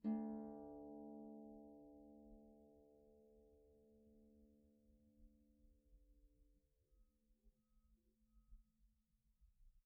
KSHarp_D2_mf.wav